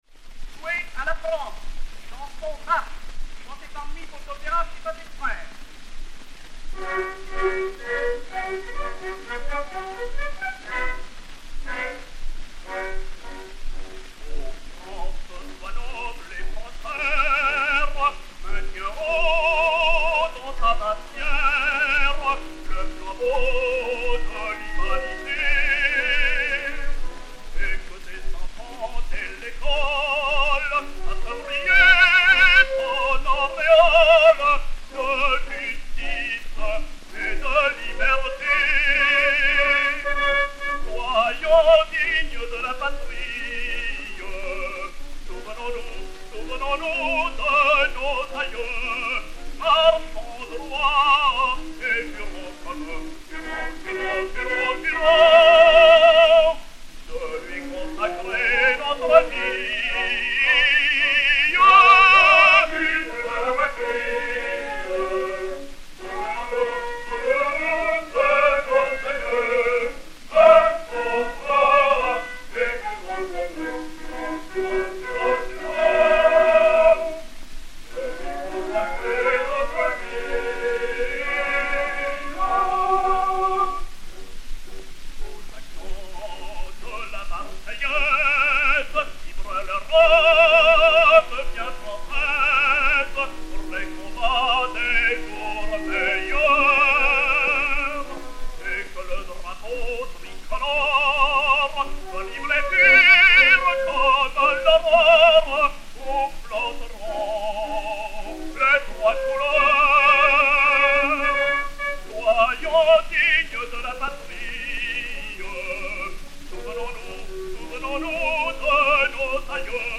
chanson de marche (par.
Orchestre
Pathé saphir 90 tours 4564 [a : 29 cm ; b : 21 cm], enr. à Paris en 1906/1907